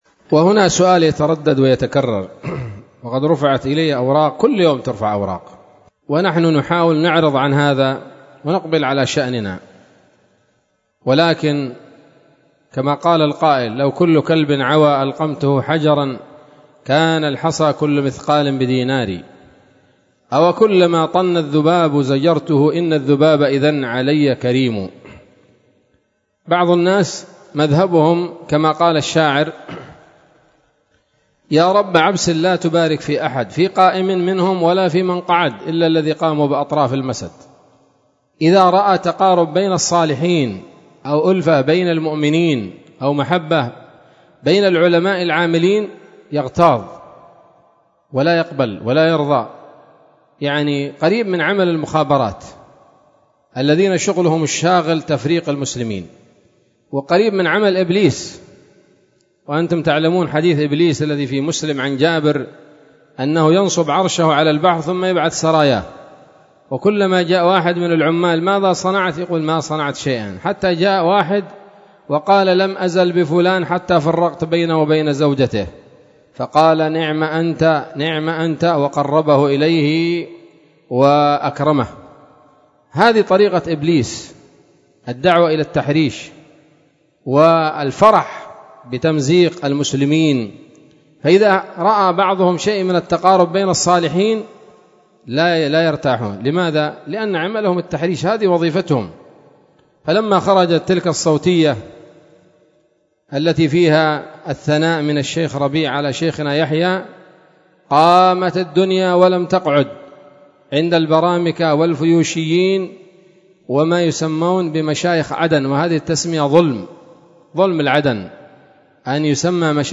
كلمة بعنوان
ليلة الجمعة 23 محرم 1447 هـ، دار الحديث السلفية بصلاح الدين